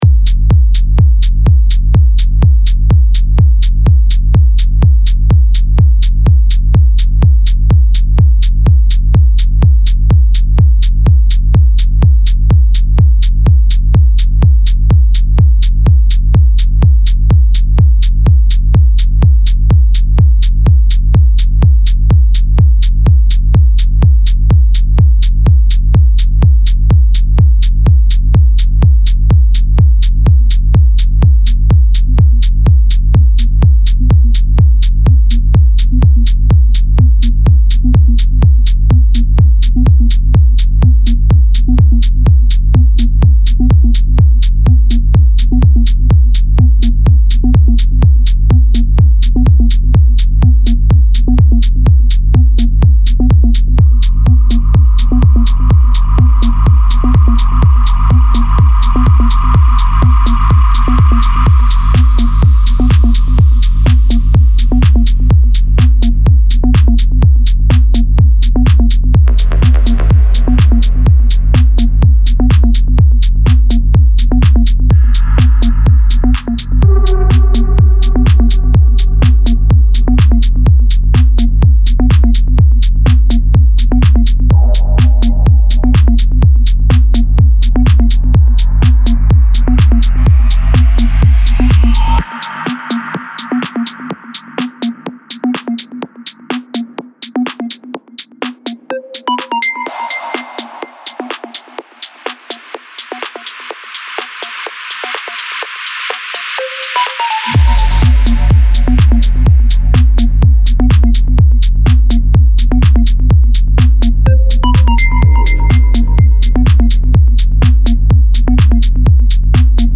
Band : Techno